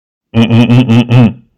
infinitefusion-e18/Audio/SE/Cries/REGIROCK.mp3 at releases-April